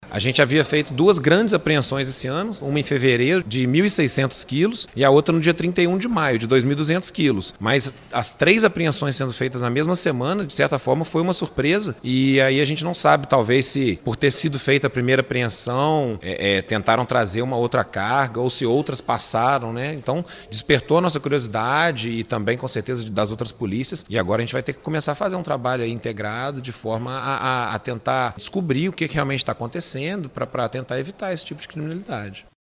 Policial rodoviário